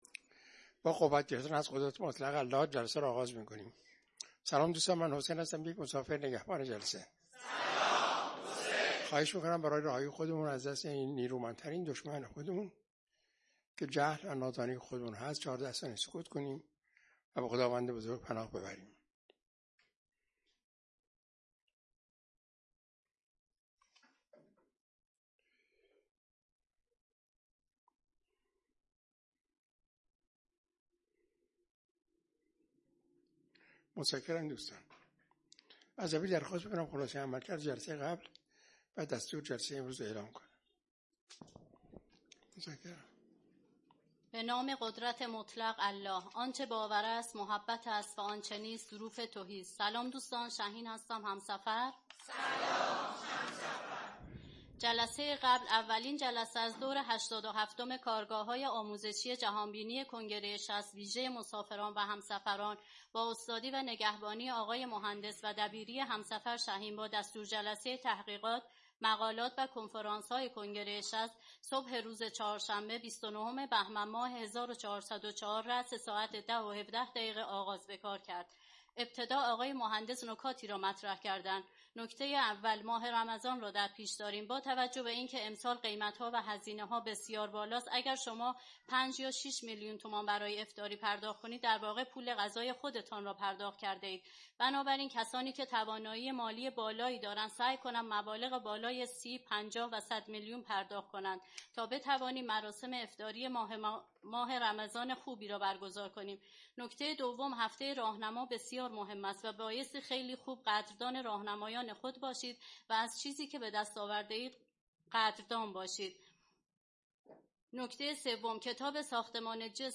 کارگاه آموزشی جهان‌بینی؛ وادی چهاردهم : عشق ، محبت